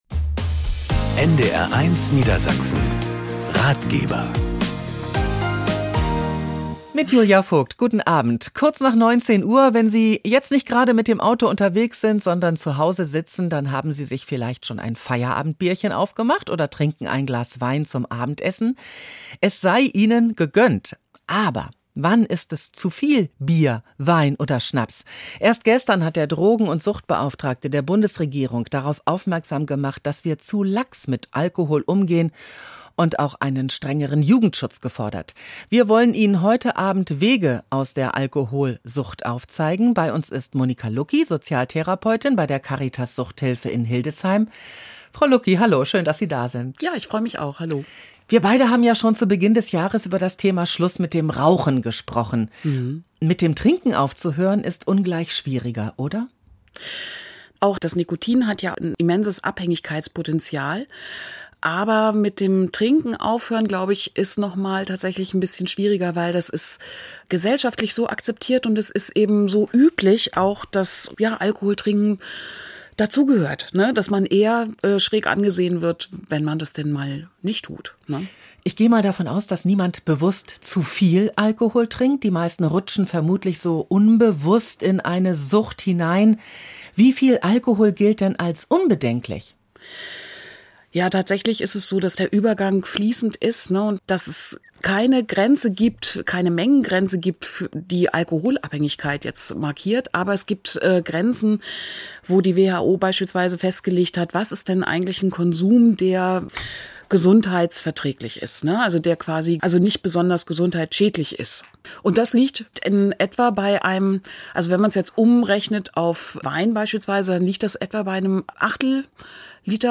Der "Ratgeber" bietet Beratung, Wegweisung und Lebenshilfe in allen Lebensbereichen – ob zu Verbraucher-, Technik- oder Finanzthemen. Experten erklären die immer komplizierter werdende Welt so, dass sie jedermann verstehen kann. Je nach Thema ist die Sendung mit einer Beratungsaktion am Hörertelefon verbunden, bei der Experten im Funkhaus Hörerfragen beantworten.